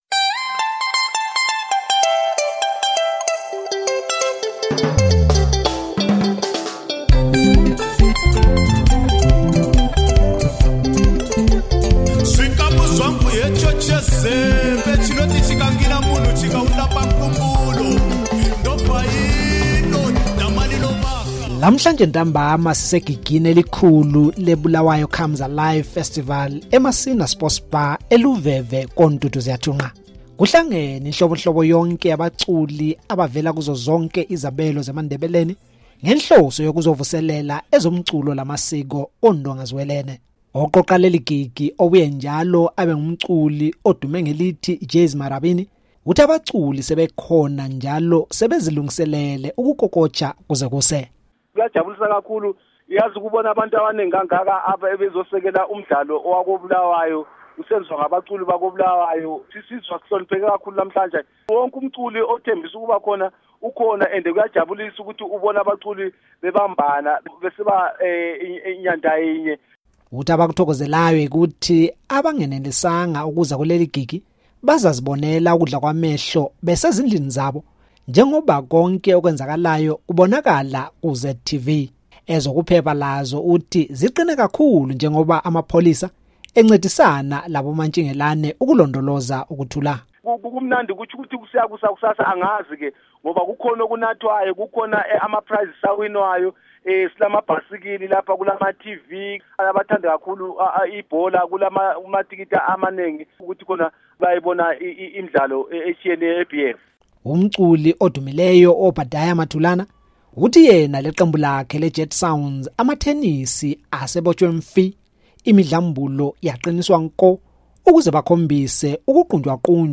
Bulawayo Concert